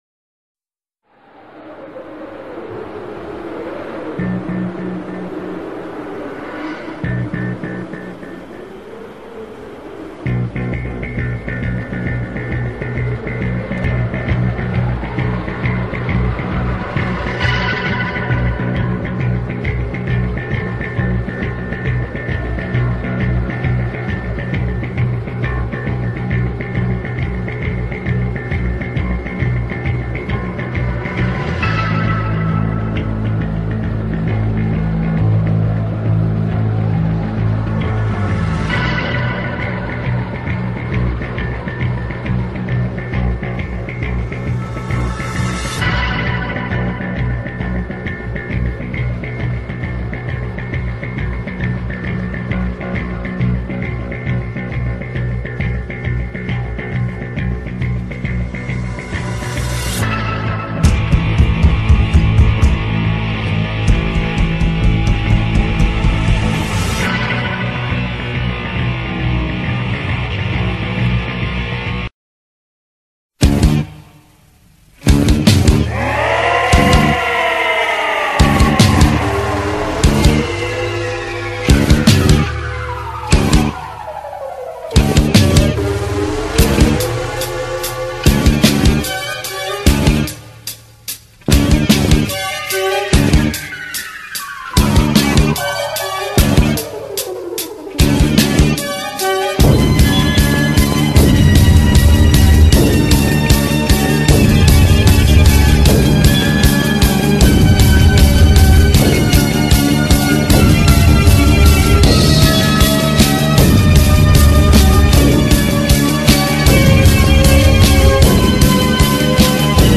アブドーラ・ザ・ブッチャー入場曲
アンドレ・ザ・ジャイアント入場曲